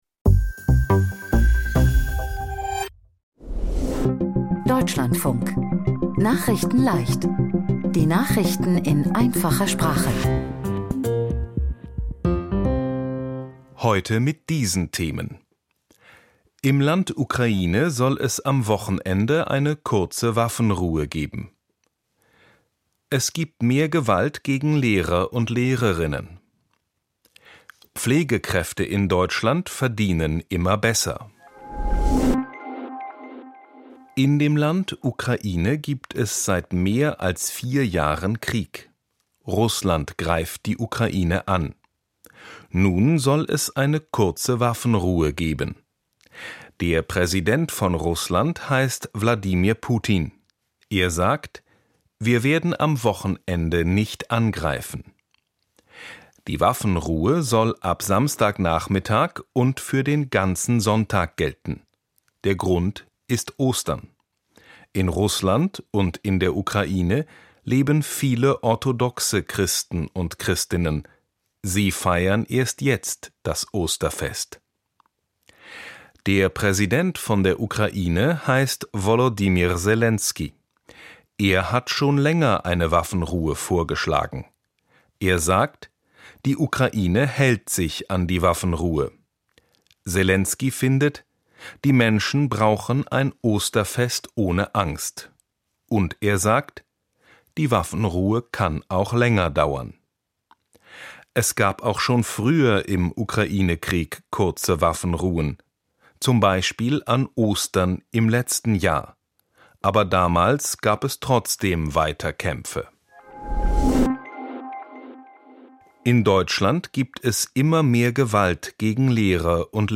Nachrichtenleicht - Nachrichten in Einfacher Sprache vom 10.04.2026